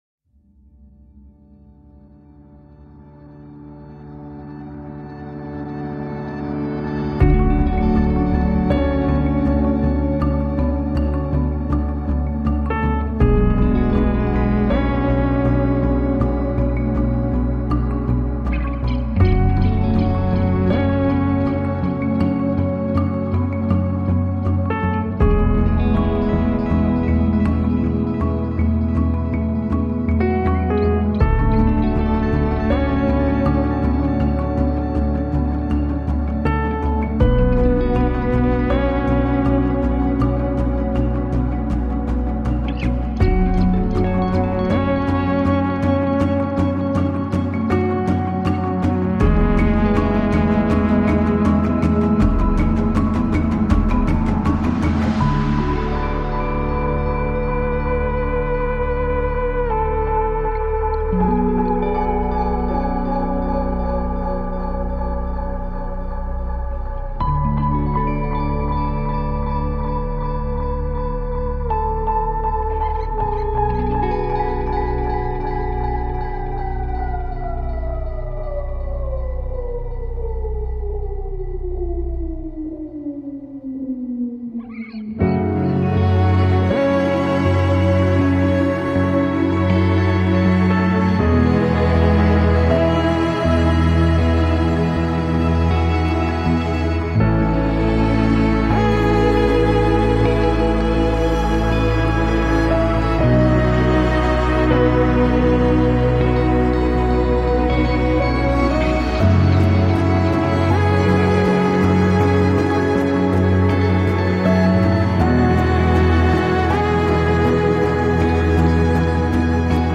Hélas, l’ambiance synthés 80’s vient gâcher tout ça.